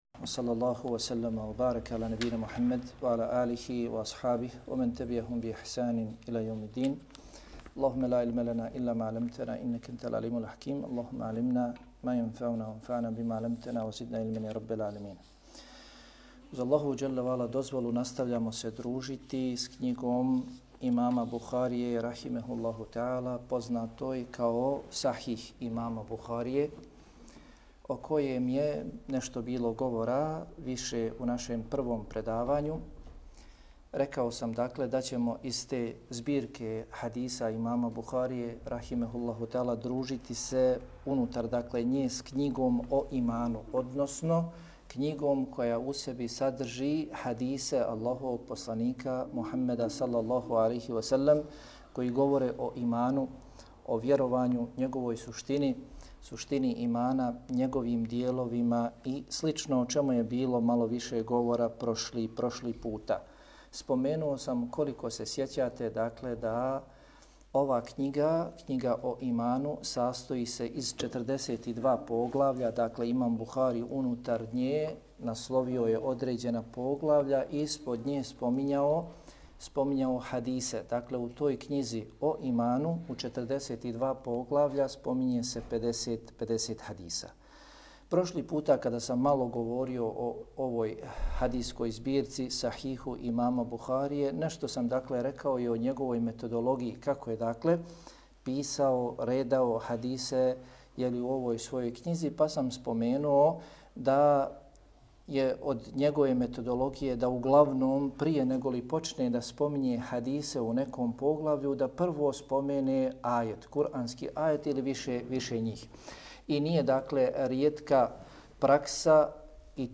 Predavanje